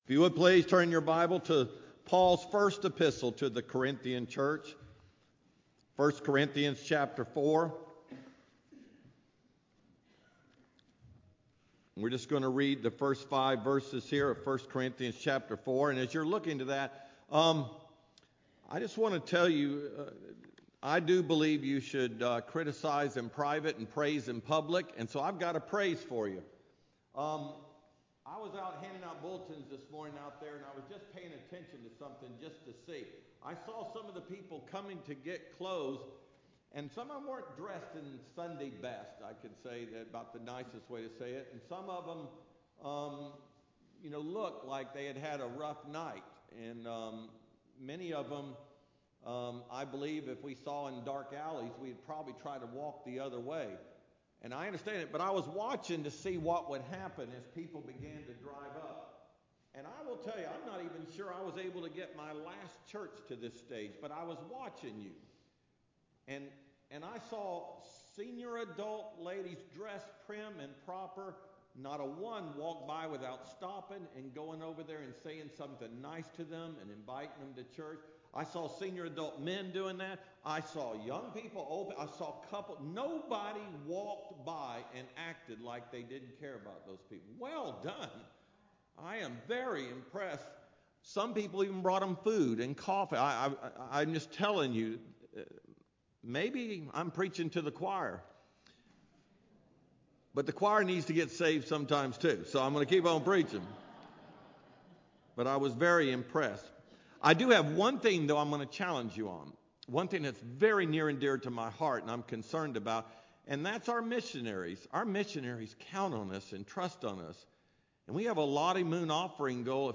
Sermon-1-18-15-PM-CD.mp3